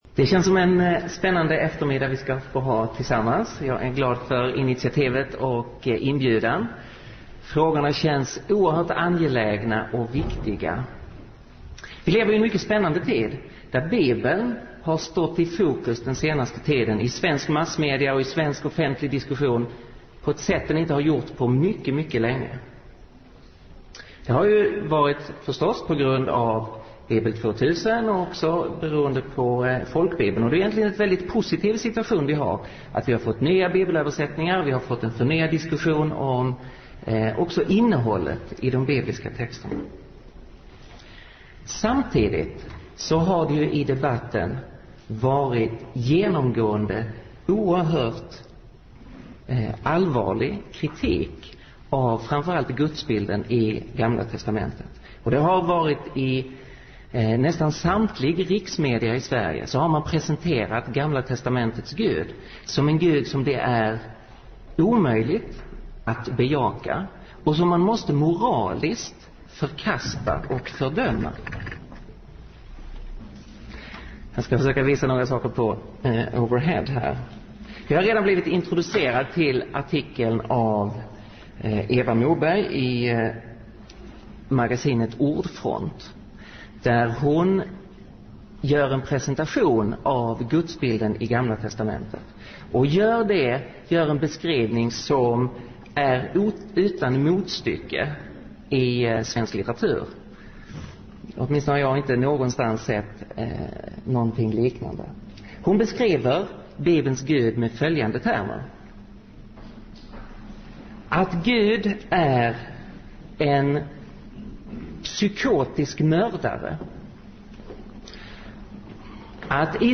Föredrag